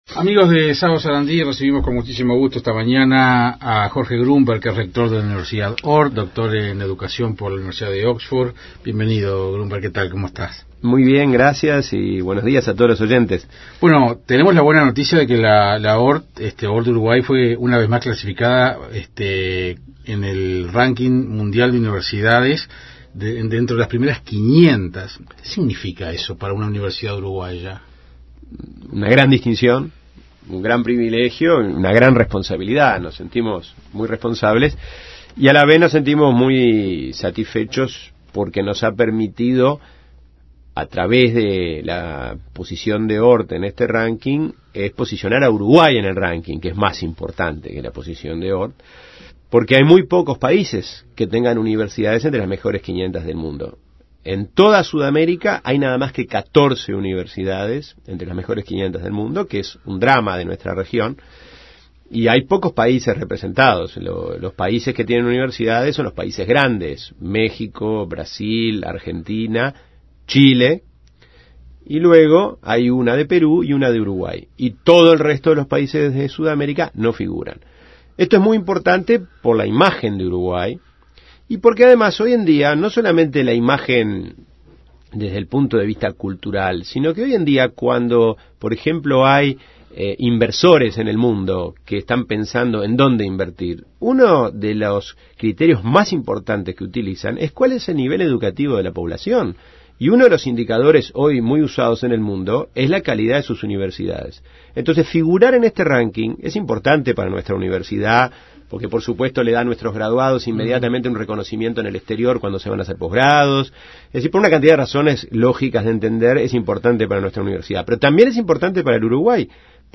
Entrevista en Radio Sarandí